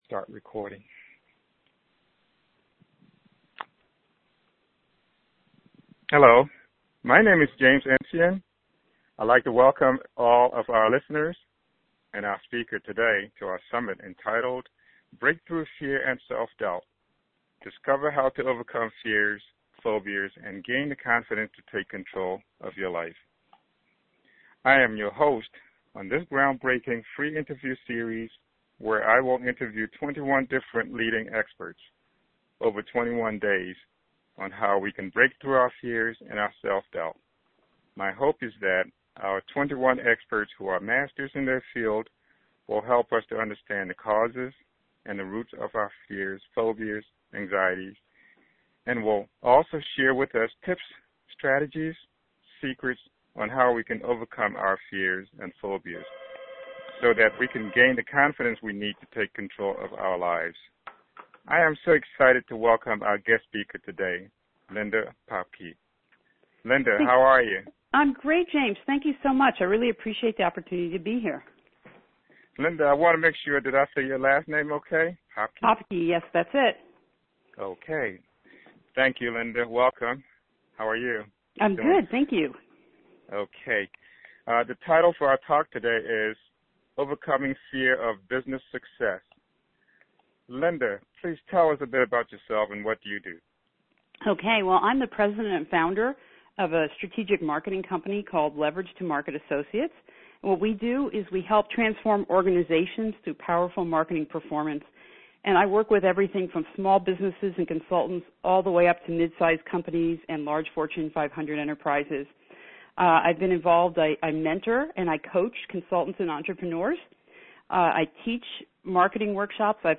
interviewed
Break Through Fear and Self-Doubt Telesummit